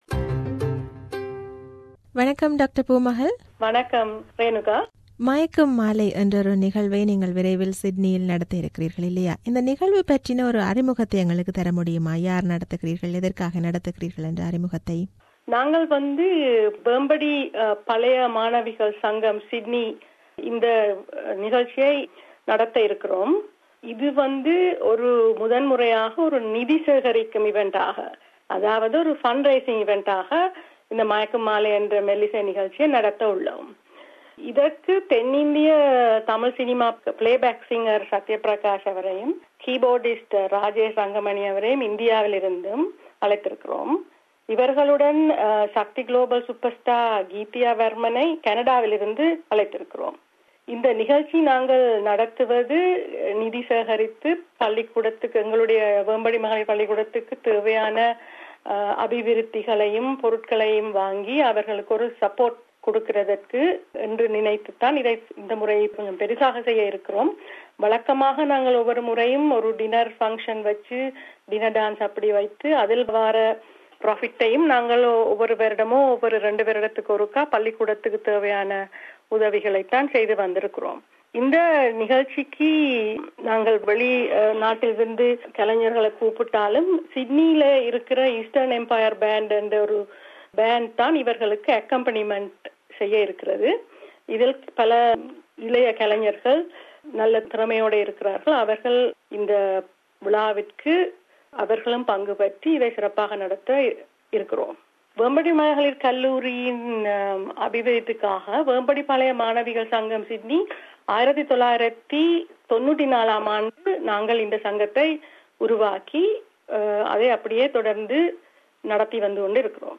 This is an interview